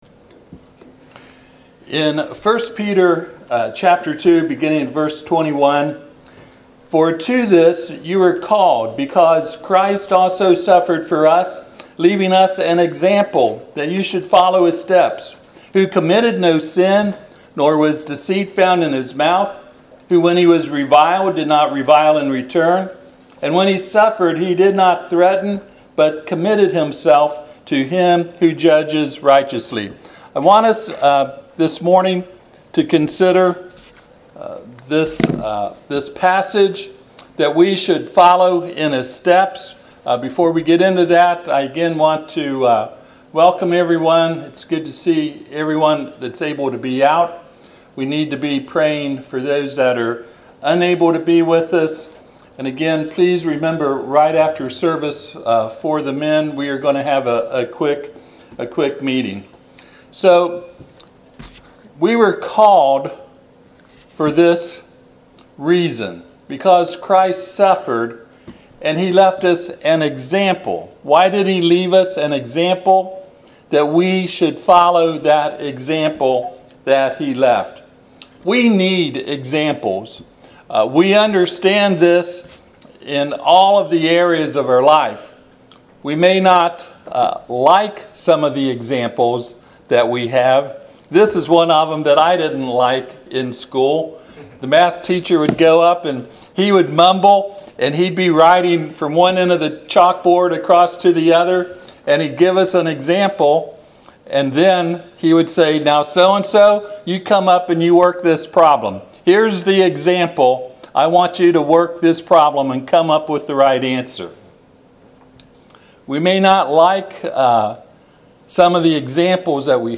Passage: 1Peter 2:21-23 Service Type: Sunday AM